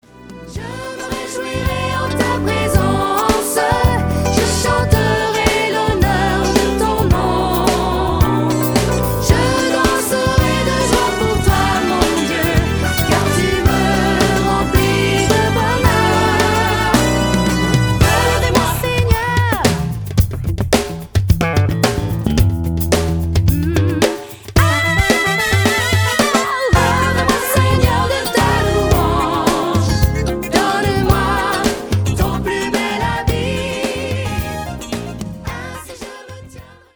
production 100% studio
Ces chants de style très variés
Format :MP3 256Kbps Stéréo